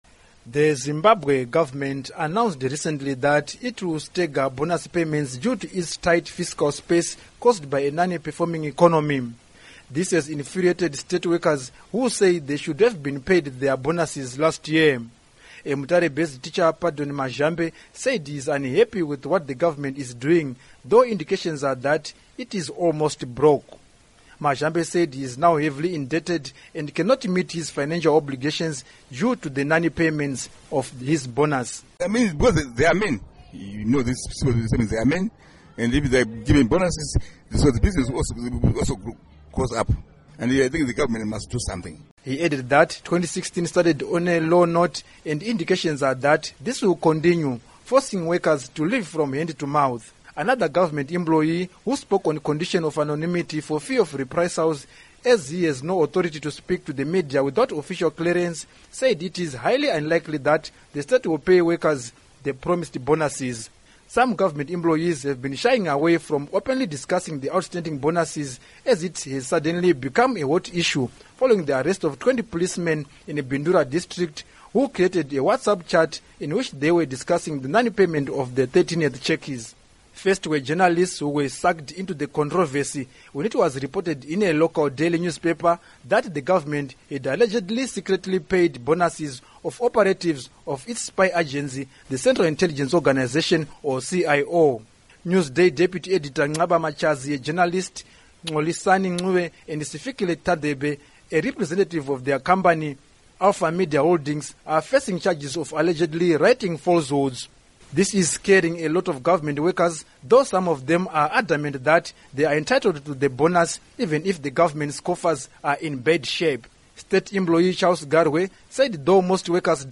Report on Bonuses